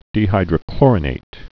(dē-hīdrə-klôrə-nāt)